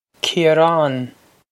Ciarán Keer-ahn
This is an approximate phonetic pronunciation of the phrase.